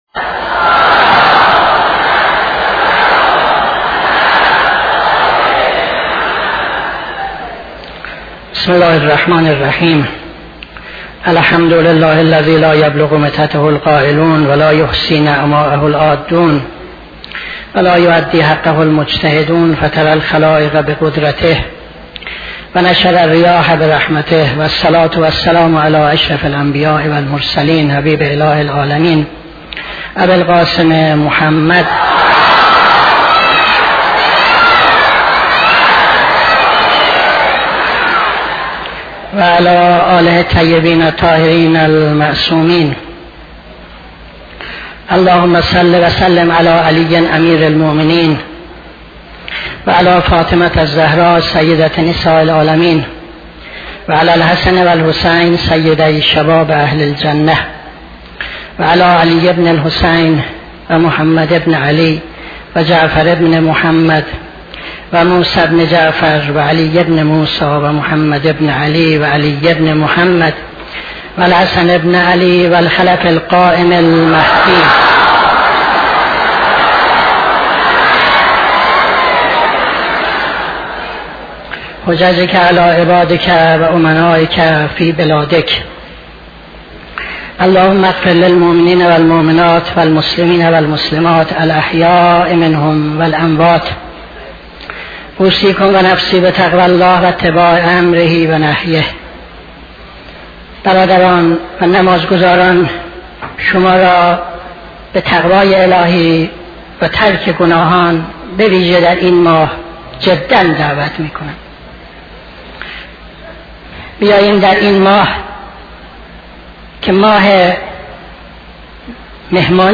خطبه دوم نماز جمعه 19-10-76